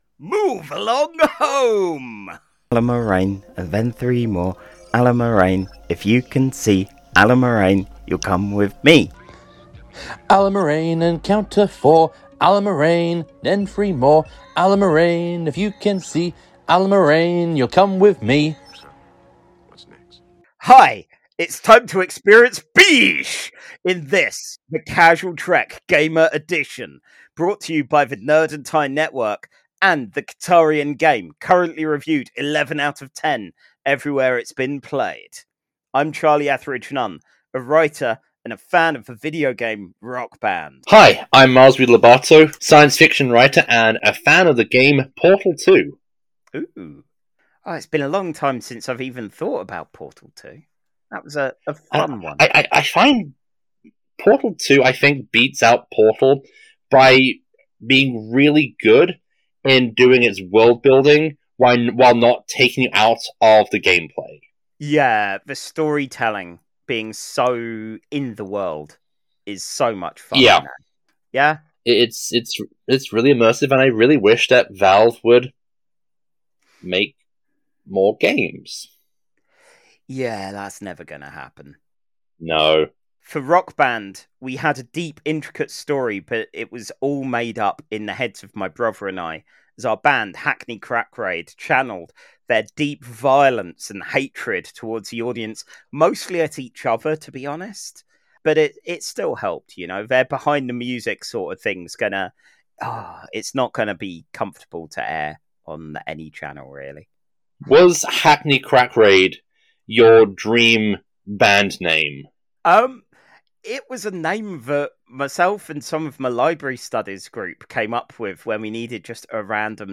Casual Trek's still in recovery mode from life and the festive period and we've wanted to fix the "Gamers" episode which was cursed with bad attempts to compress the file.